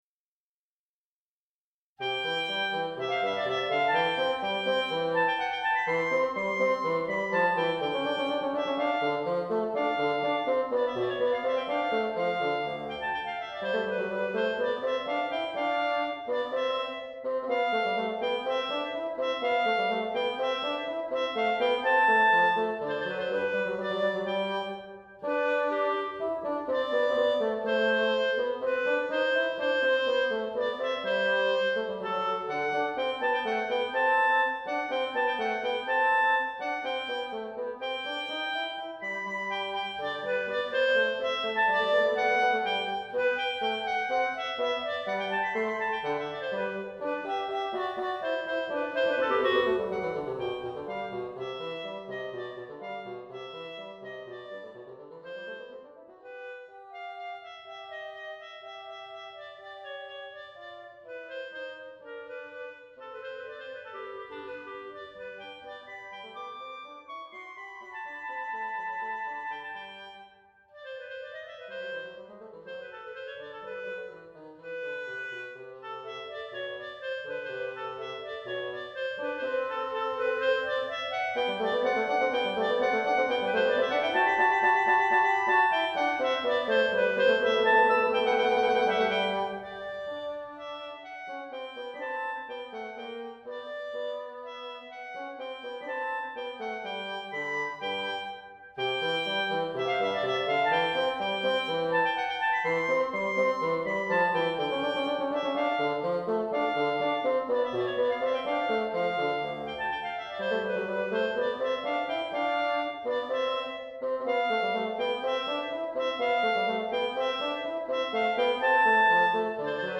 Chamber music for Wind Instruments by Beethoven - Midi and Mp3 files - Ludwig van Beethoven's website...
WoO027Nb1_DuoClarinetteBasson.mp3